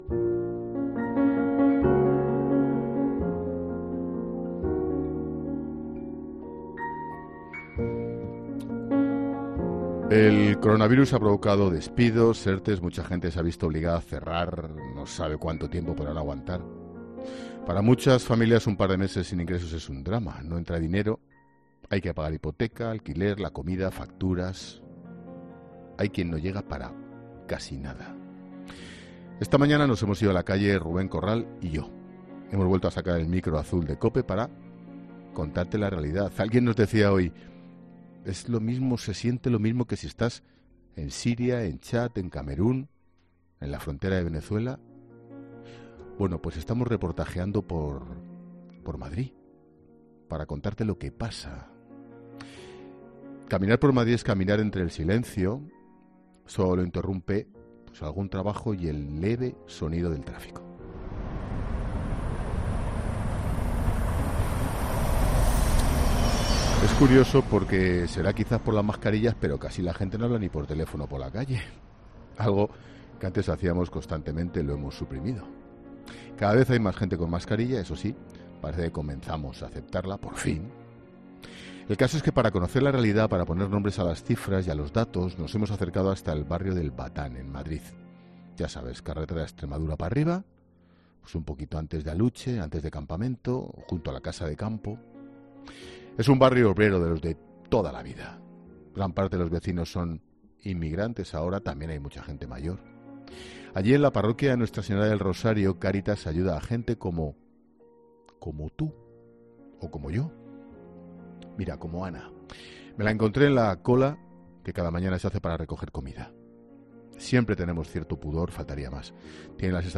Esta mañana nos hemos ido a la calle. Hemos vuelto a sacar el micro del estudio para contarte la realidad.